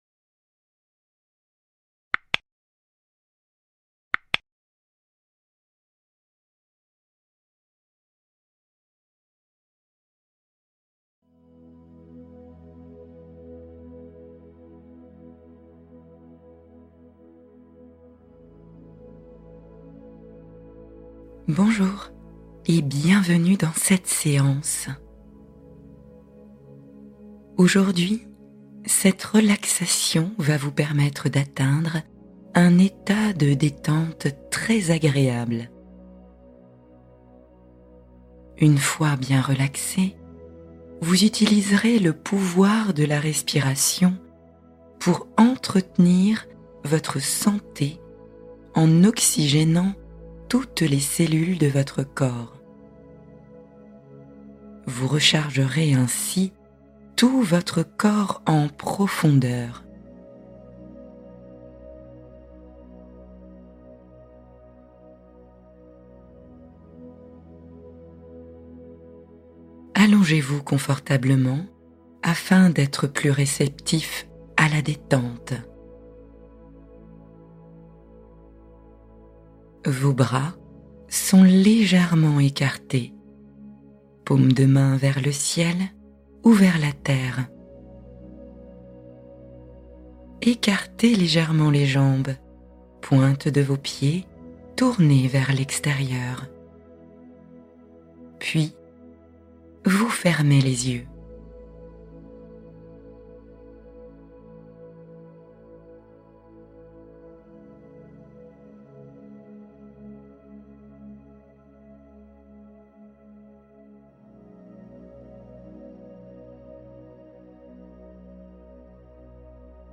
Oxygène et Vie : Méditation respiratoire pour régénérer chaque cellule